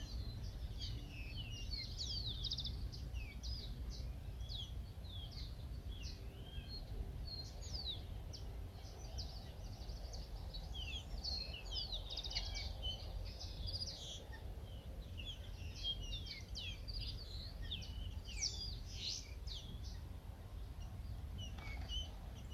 Tordo Músico (Agelaioides badius)
Nombre en inglés: Greyish Baywing
Localidad o área protegida: Reserva Natural Paititi
Condición: Silvestre
Certeza: Observada, Vocalización Grabada
Tordo-Musico.mp3